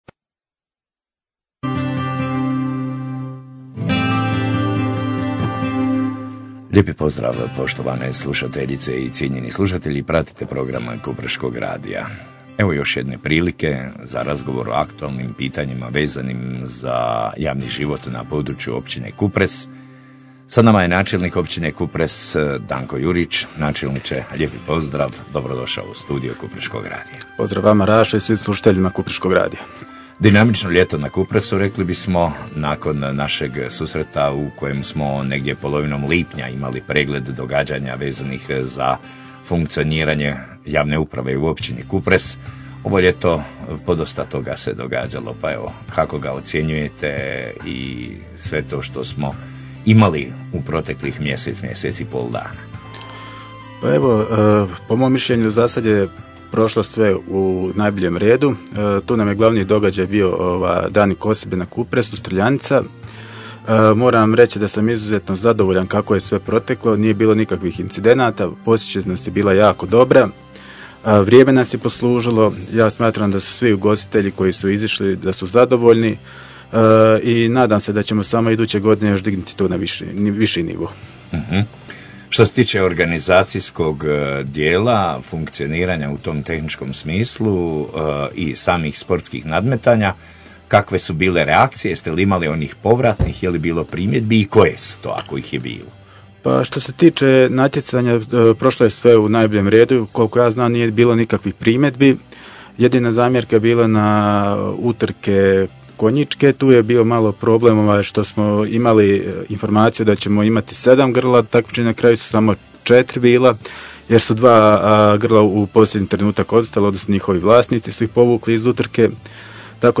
Kojom dinamikom će radovi biti izvođeni i u koje vrijeme ?-pitali smo načelnika.
Pitali smo načelnika kako funkcionira odnos između izvršne i zakonodavne vlasti, gdje je proces arbitraže sada i kada bi to trebalo biti gotovo? Odgovore na ova i druga pitanja poslušajte u razgovoru ovdje: aktualna_zbivanja_u_Kupresu_na.mp3